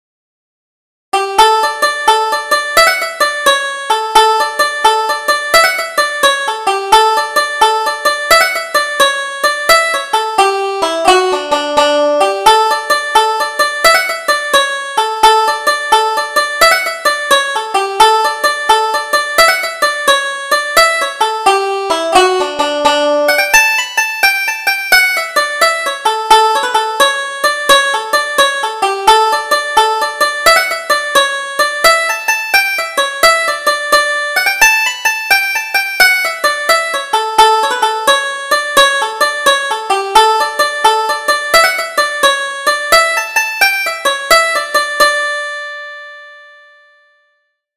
Double Jig: The Cat and the Bacon